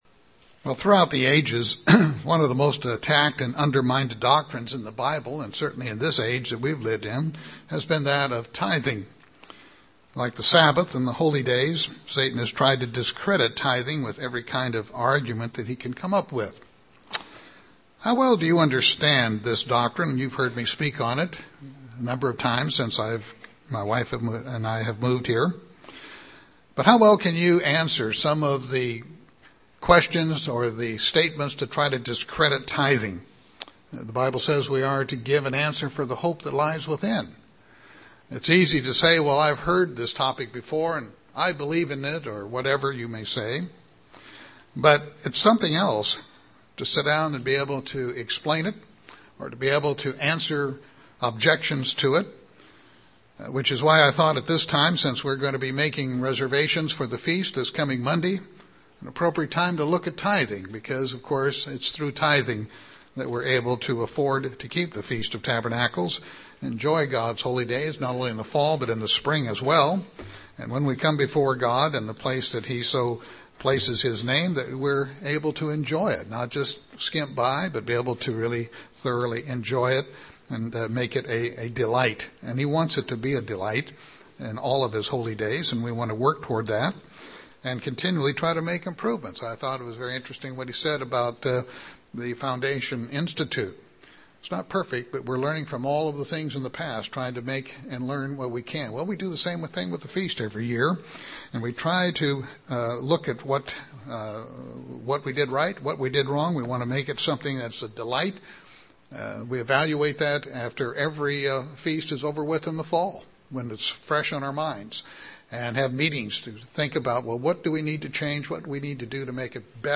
6-2-12 Sermon.mp3